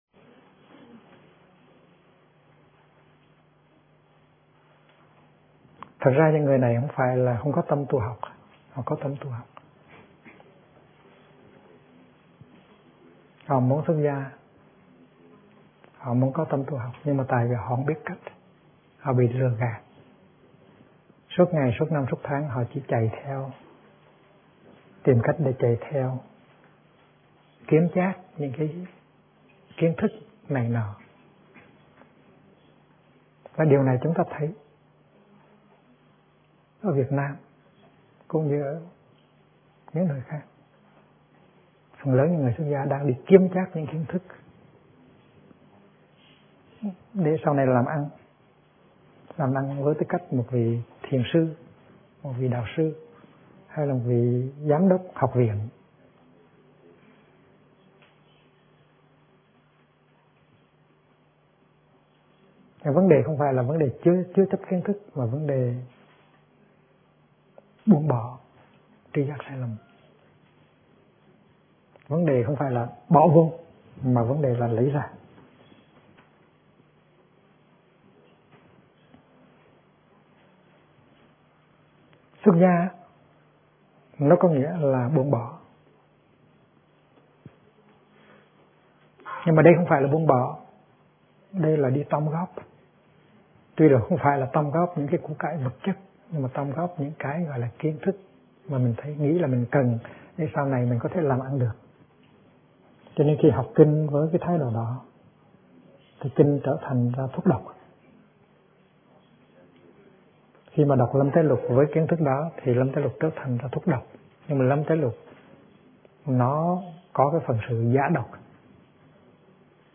Kinh Giảng Tri Kiến Chân Thật - Thích Nhất Hạnh